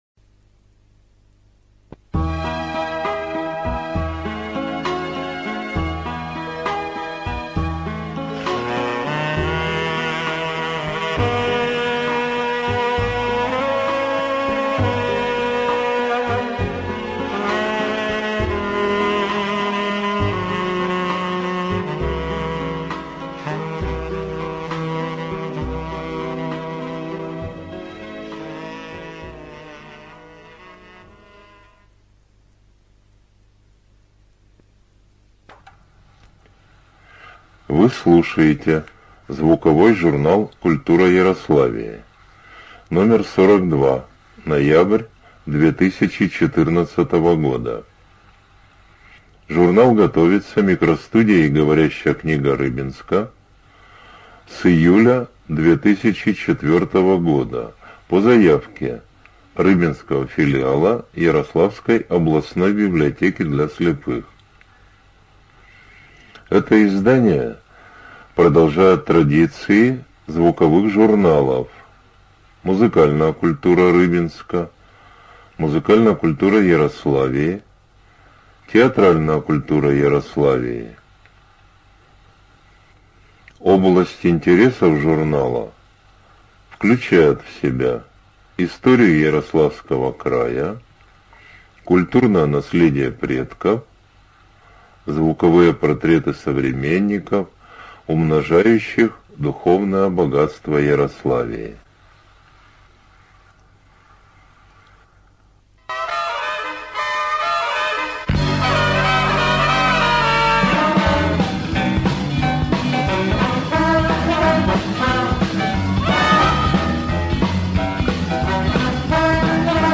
Студия звукозаписиГоворящая книга Рыбинска